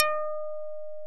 Index of /90_sSampleCDs/OMI - Universe of Sounds/EII Factory Library/85 Fretless Bass&Plucked Piano